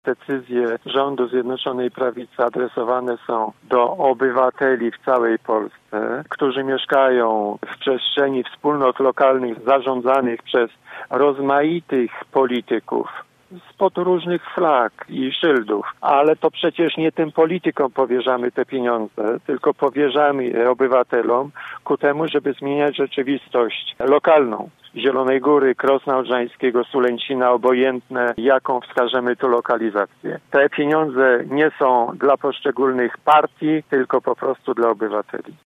Pieniądze mają zmieniać rzeczywistość lokalną – mówił poseł:
Jacek Kurzępa był gościem Rozmowy po 9.